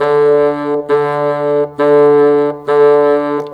Rock-Pop 01 Bassoon 04.wav